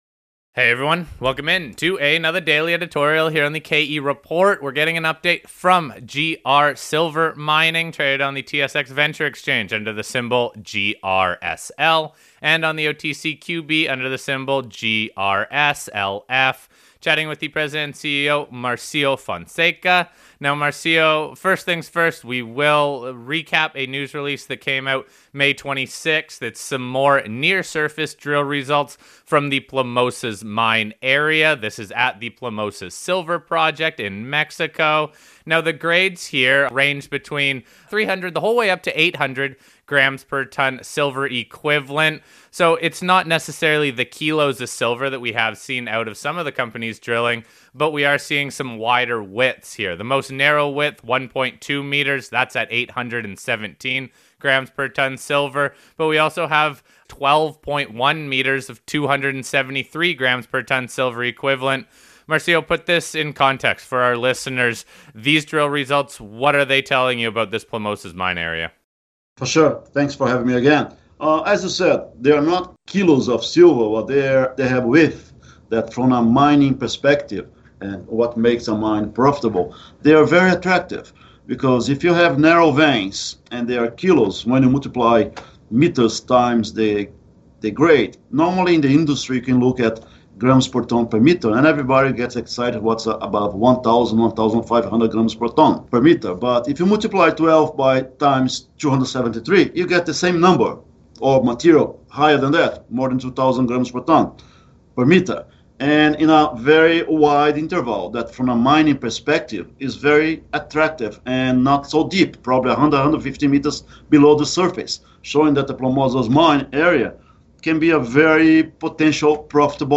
We focus on the overall results from a potential mining scenario considering the longer width. The Company is moving towards an updated resource estimate in mid-July, and is already planning future drilling with 2 rigs for the Plomosas Project and 1 rig at the San Marcial Project. We wrap up the interview with the initial work starting at both newly acquired projects, La Trinidad and Cimarron.